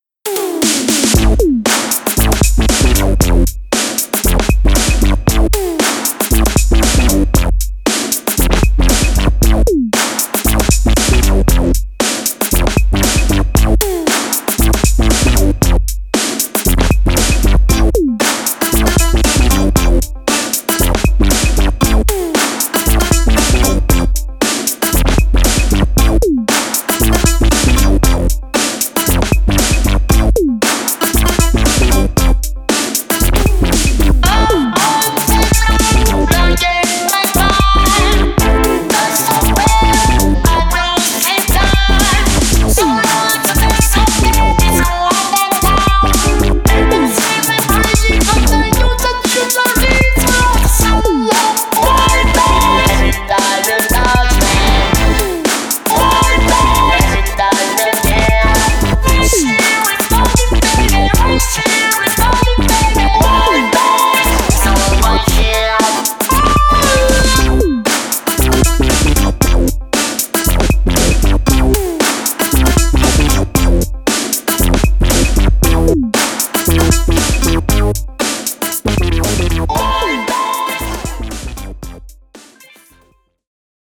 ジャンル(スタイル) JAPANESE / SOUL / FUNK / CLUB